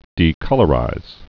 (dē-kŭlə-rīz)